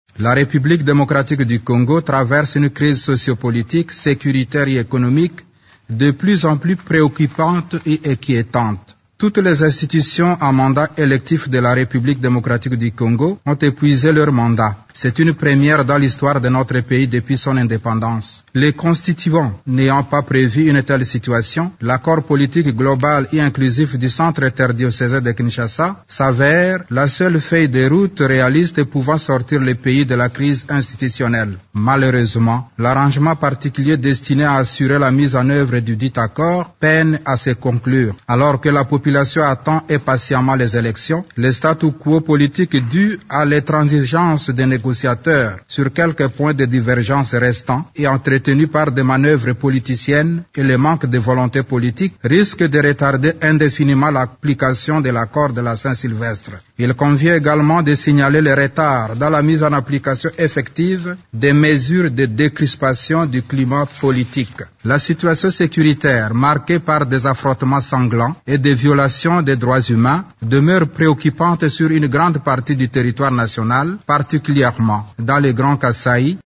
S’exprimant mardi 21 mars au Conseil de sécurité de l’ONU, le président de la Conférence épiscopale nationale du Congo (CENCO) a reconnu que « l’arrangement particulier pein[ait] à se conclure », tout en réaffirmant que l’accord du 31 décembre reste la seule voie de sortie de crise institutionnelle en RDC.
Vous pouvez écouter un extrait du discours de Mgr Utembi :